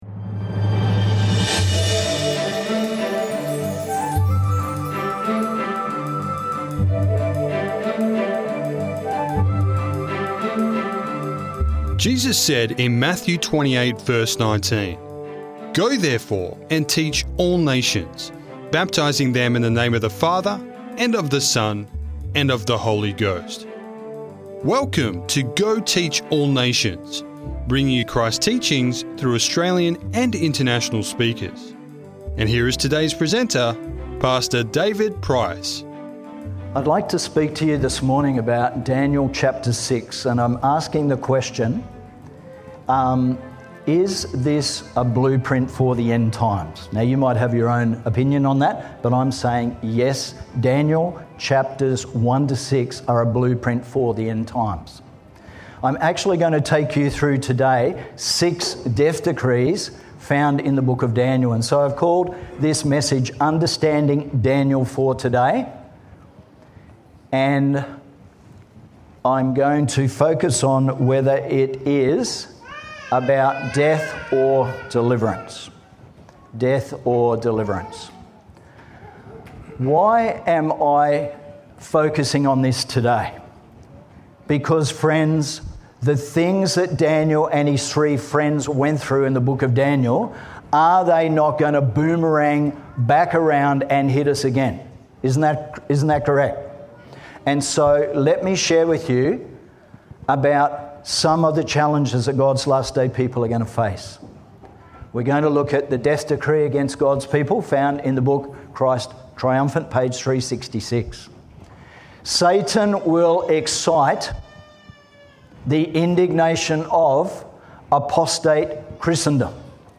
Uncover the lessons that Daniel offers for living in the last days. This message was made available by the Wauchope Seventh-day Adventist church.